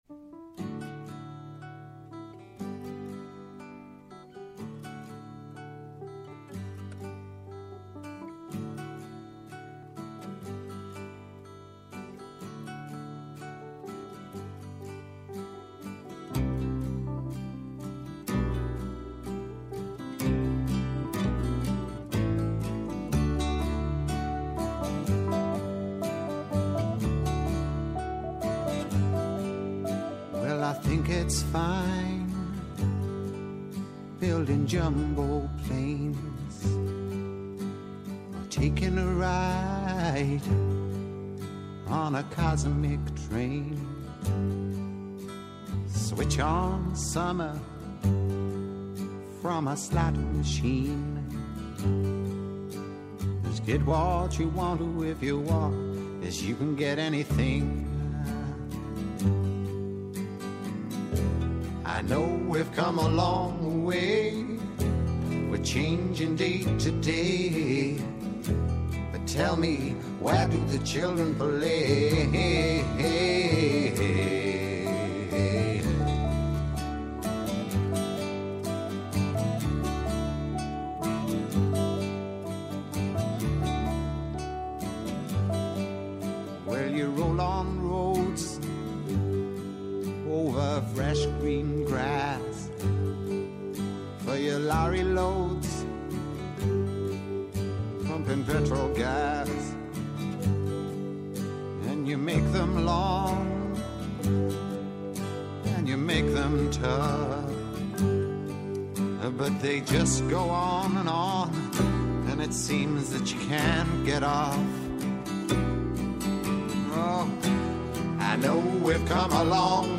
Απόψε, στο στούντιο του Α’ Προγράμματος